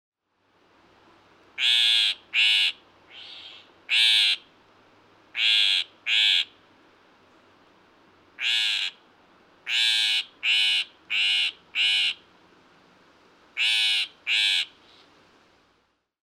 Звуки сорокопута
Northern Shrike Lanius borealis The Arctic Butcherbird